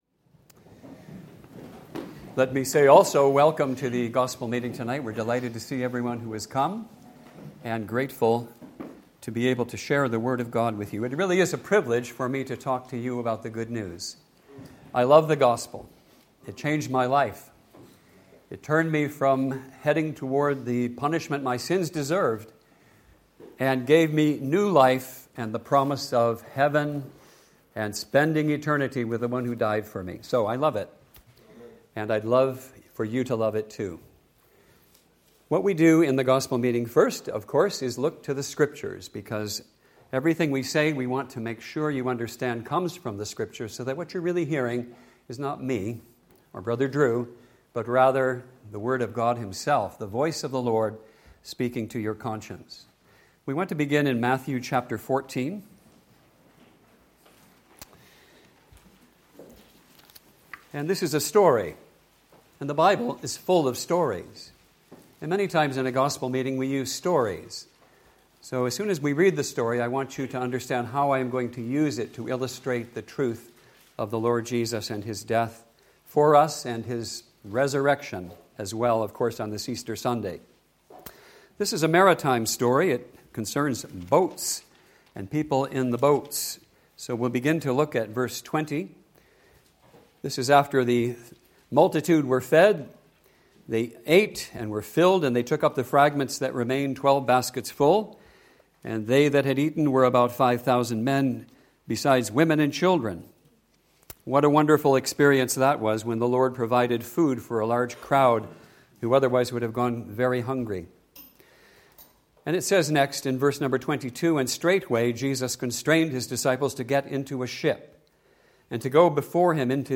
EASTER CONF 2025:
2025 Easter Conference